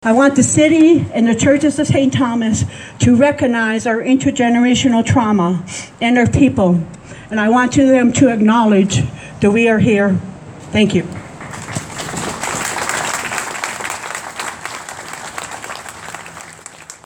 The one-hour event was a commemoration of Orange Shirt Day and National Day for Truth and Reconciliation.